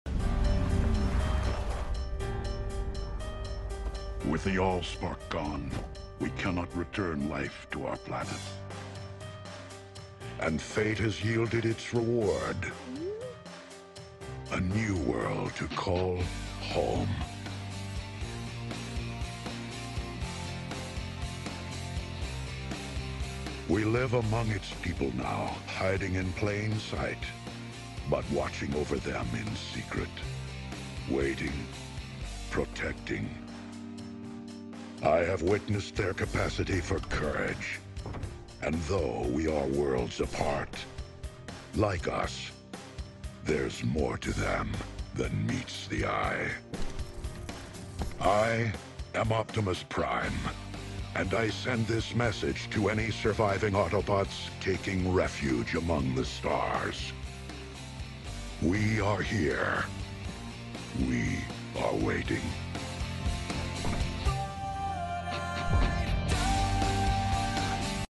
The Most Iconic Speech In Sound Effects Free Download